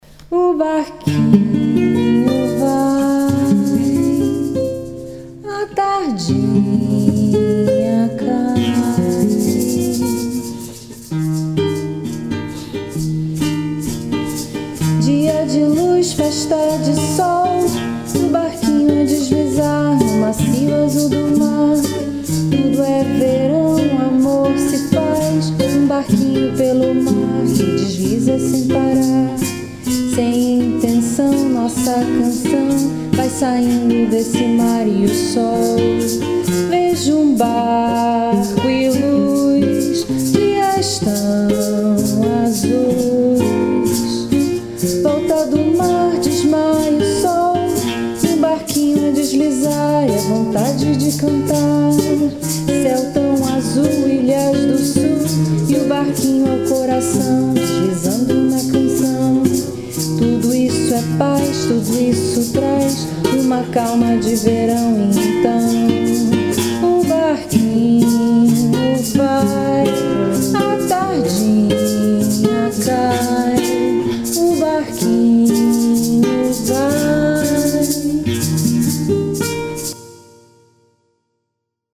perteneciente a Bossa nova